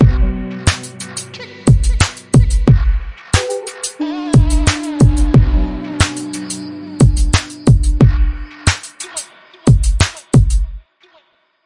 迷你嘻哈节拍 " 嘻哈液体
描述：带有环境大气层的鼓环，适合寒冷的氛围
Tag: 的PERC 环境 鼓环 敲击循环 液体